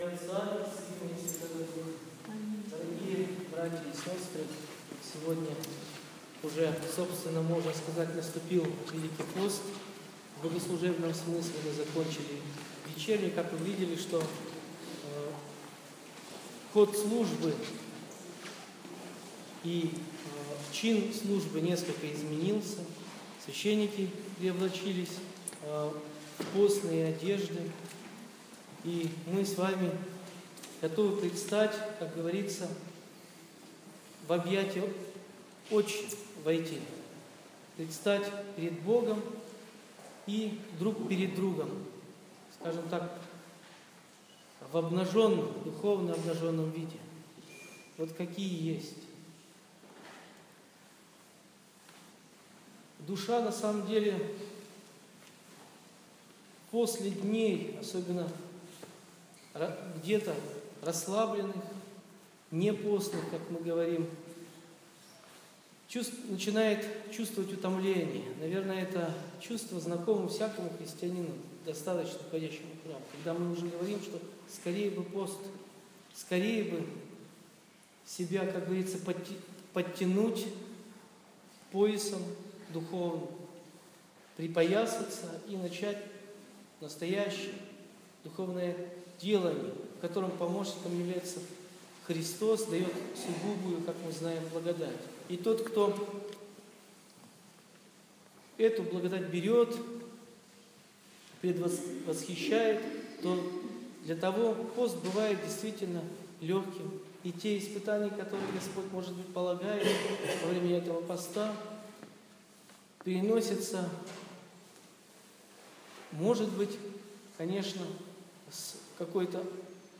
Слово в Прощеное воскресенье